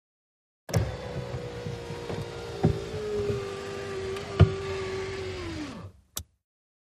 VEHICLES - AUTO ACCESSORIES: Electric window, open and close.